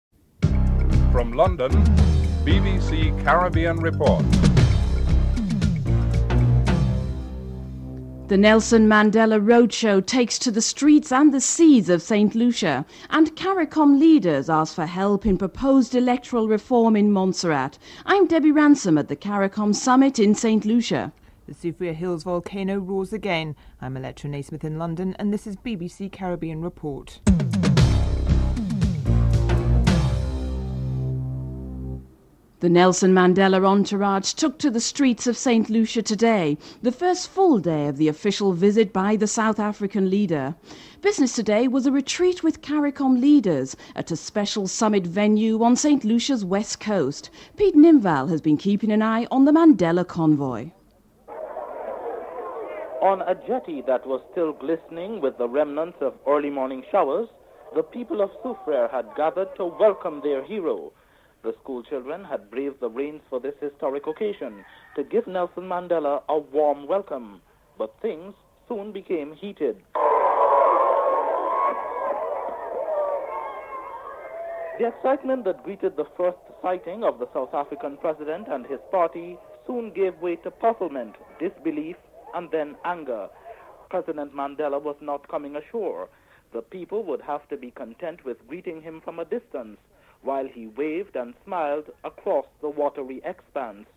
Sir Shridath Ramphal is interviewed (06:15-07:40)
Chief Minister David Brandt is interviewed (07:41-10:37)